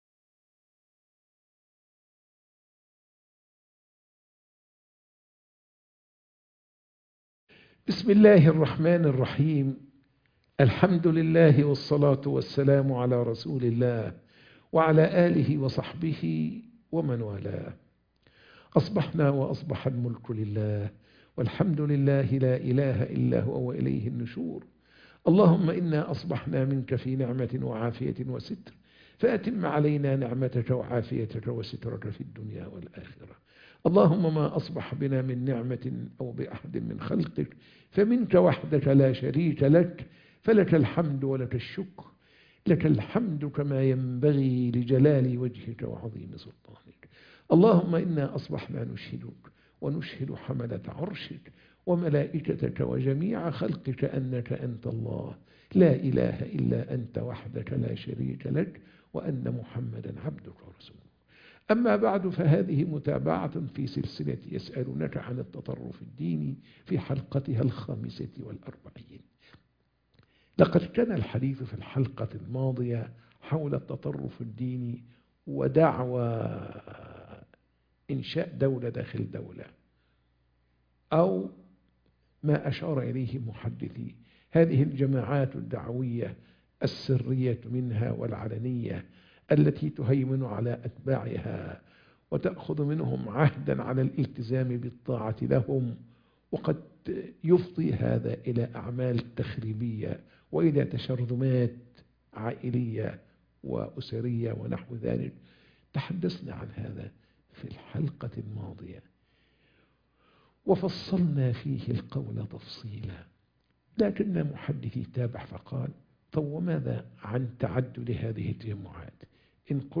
درس الفجر - يسألونك عن التطرف الديني